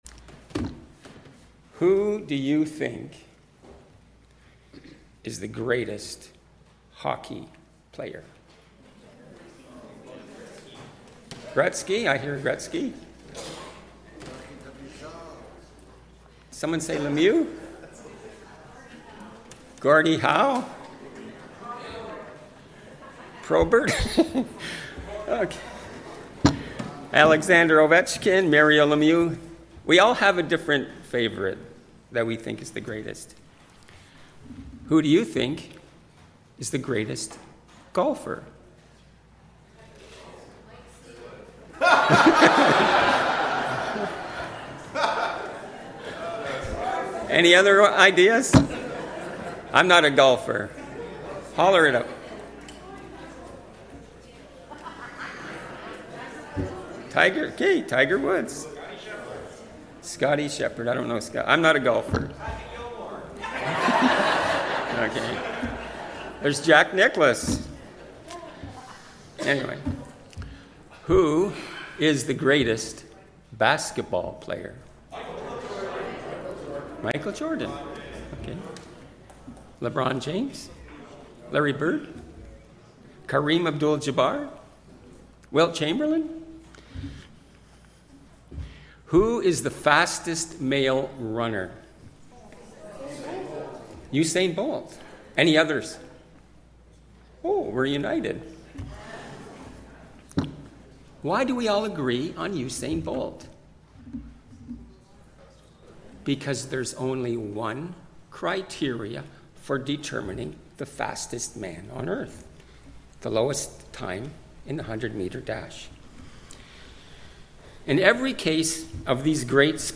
Preached by: Guest Speaker